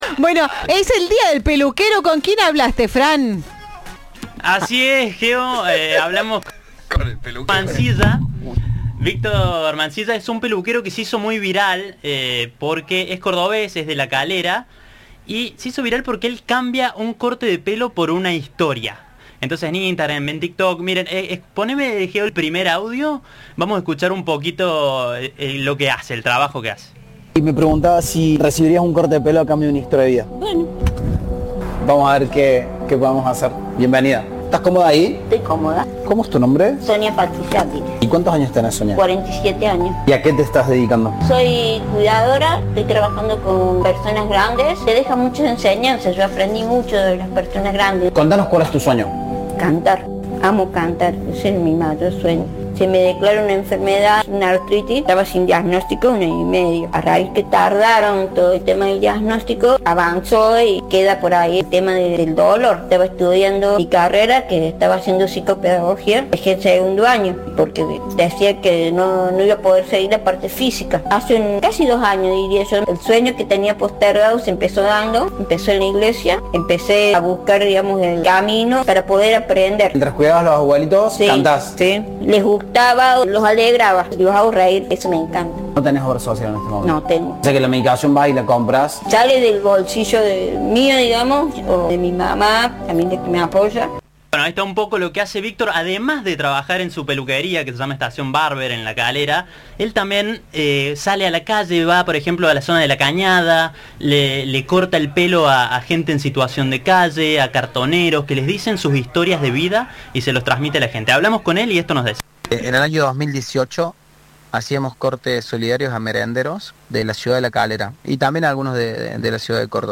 En diálogo con Cadena 3
Entrevista